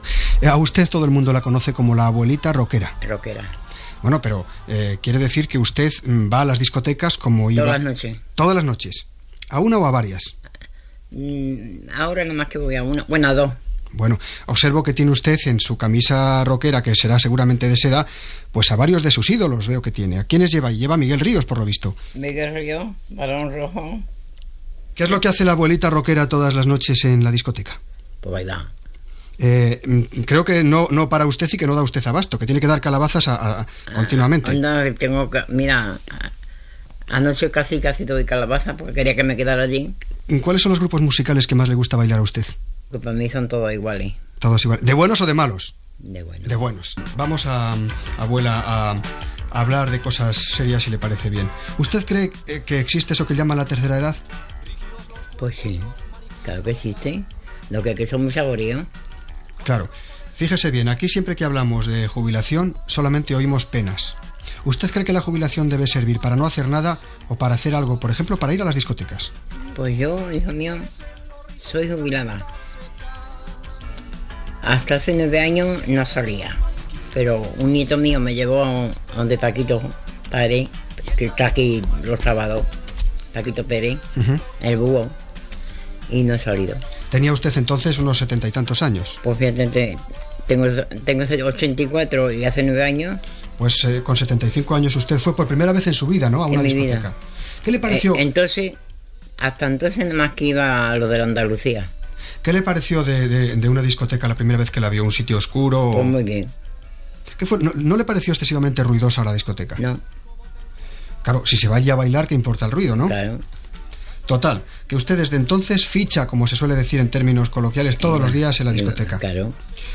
Entrevista
Entreteniment